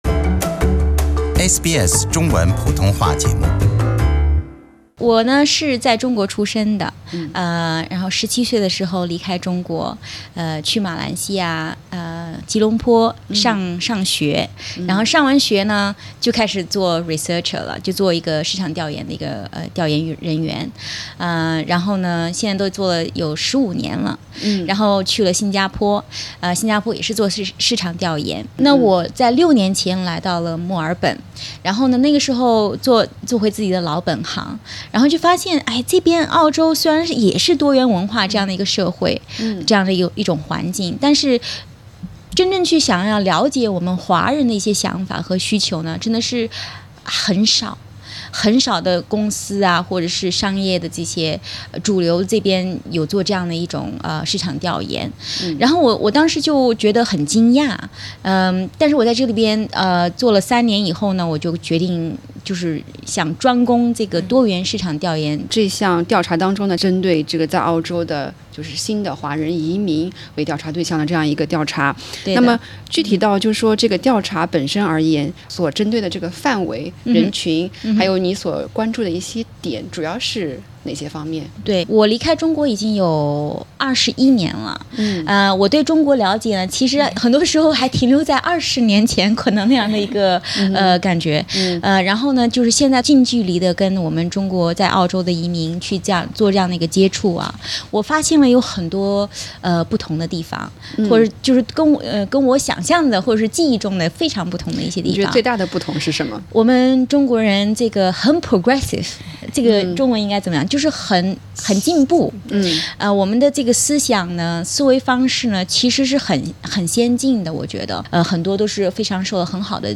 更多內容可收聽上方寀訪。